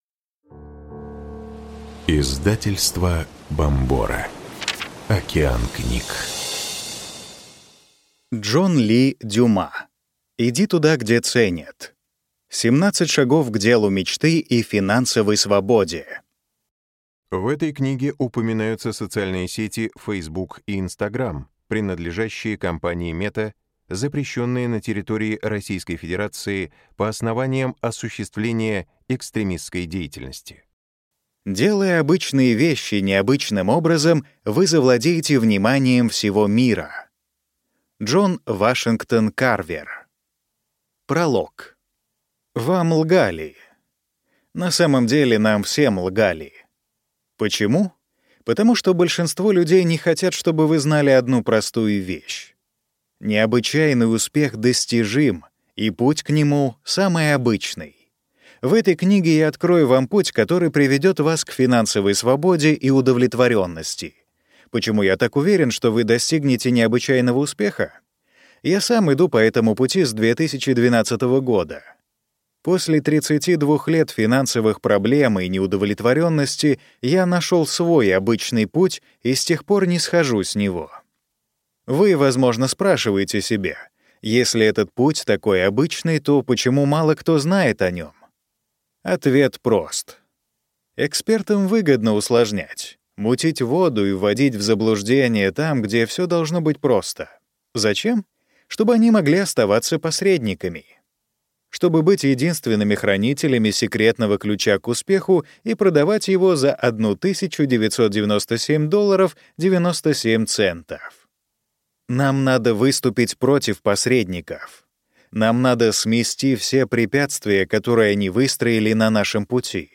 Аудиокнига Иди туда, где ценят. 17 шагов к делу мечты и финансовой свободе | Библиотека аудиокниг